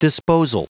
Prononciation du mot disposal en anglais (fichier audio)
Prononciation du mot : disposal